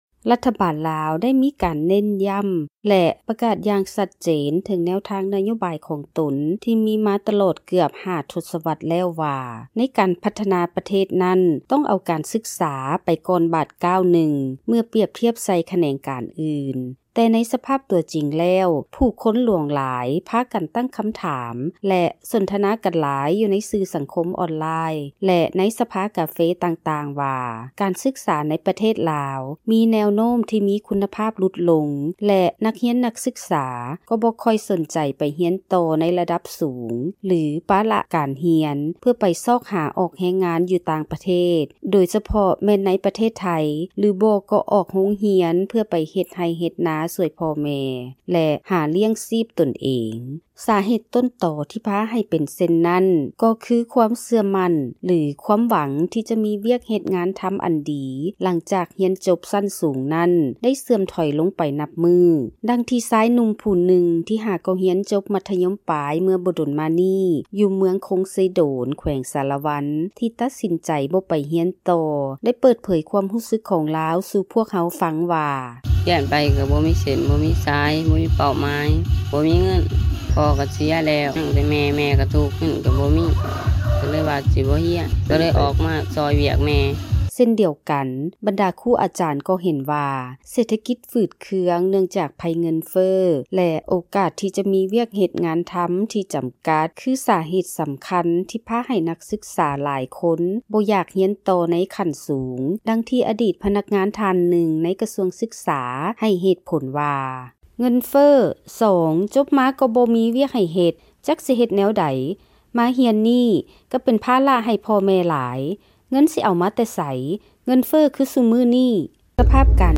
ບັນຫາໄພເງິນເພີ້ ແລະເສດຖະກິດຝືດເຄືອງທີ່ຮ້າຍແຮງຂຶ້ນ ຂະນະທີ່ມີຂີດຈໍາກັດໃນດ້ານງົບປະມານການສຶກສາ ແລະໂອກາດທີ່ຈະໄດ້ວຽກເຮັດງານທໍາ ເປັນສາເຫດເຮັດໃຫ້ນັກຮຽນ, ນັກສຶກສາມີຄວາມທໍ້ແທ້ໃຈ ແລະອອກໂຮງຮຽນກ່ອນຈົບເພື່ອໄປຊອກຫາອອກແຮງງານຢູ່ຕ່າງປະເທດ ຫລືອອກມາເຮັດວຽກງານ ກະສິກໍາເພື່ອຫາລ້ຽງຊີບຕົນເອງ ຫລືຊ່ວຍພໍ່ແມ່ນັ້ນ ເປັນຈໍານວນຫລາຍ. ນັກຂ່າວຂອງພວກເຮົາມີລາຍງານມາສະເໜີທ່ານໃນອັນດັບຕໍ່ໄປ.